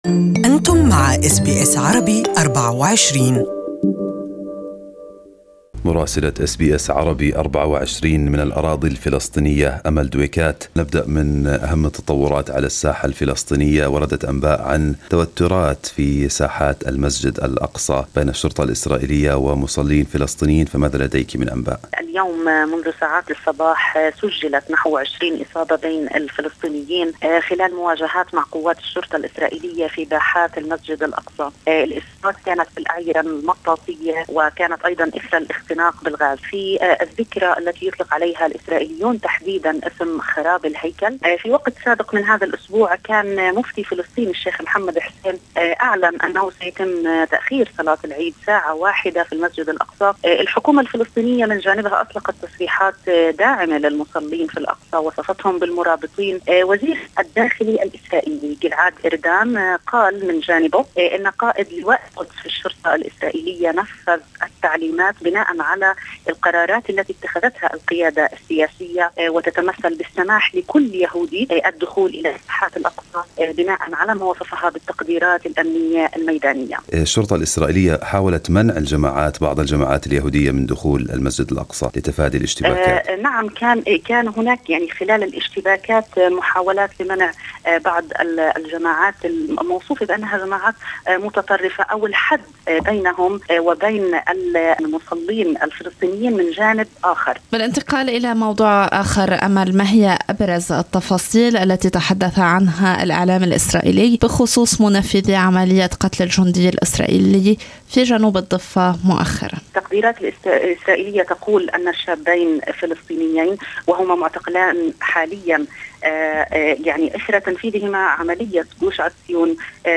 Report available in Arabic.